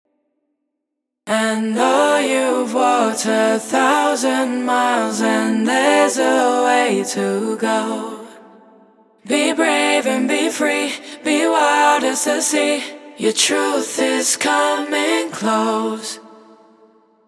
Her warm, deep, and soulful vocals bring profound emotion to everything they touch.
• 76 Unique vocal hooks – dry and wet.
• 6 Toplines & Full Vocals (including doubles, harmonies, and backing vocals).